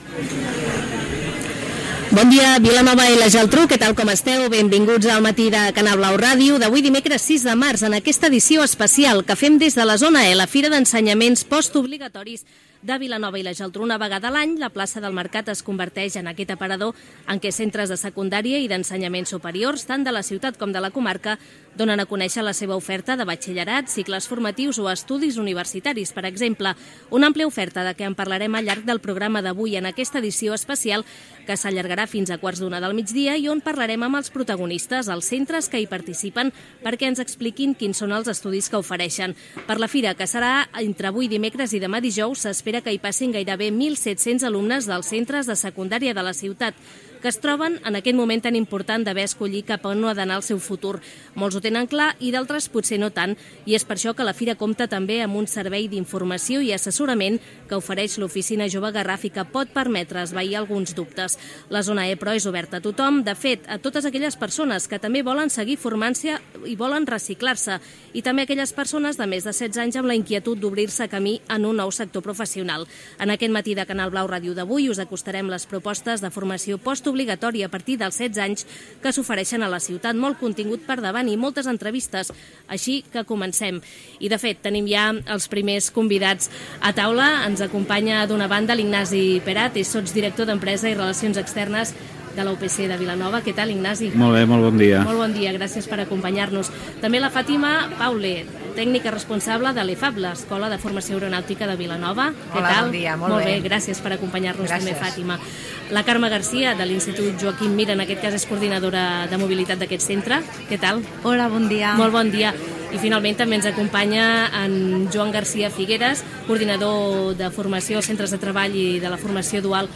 Programa especial fet des de la zona E, a la Fira d'Ensenyaments, a la plaça del Mercat.